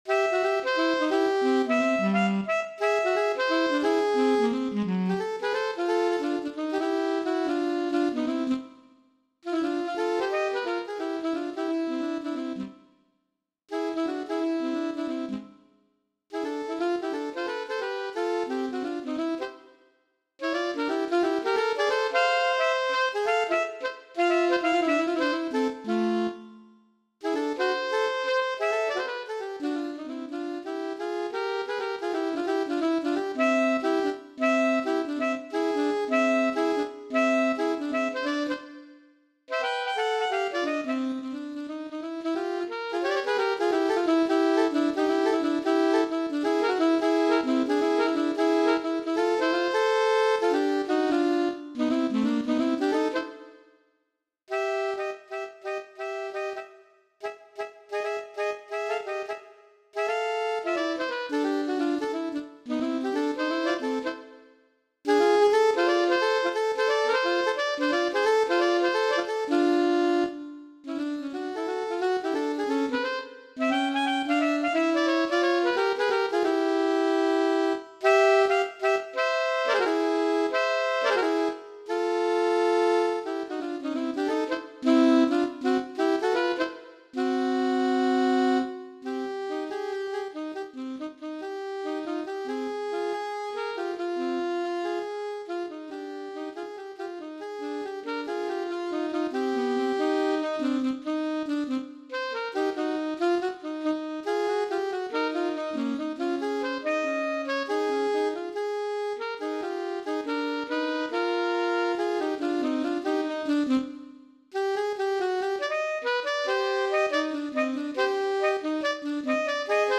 Gattung: Für 2 Altsaxophone
Besetzung: Instrumentalnoten für Saxophon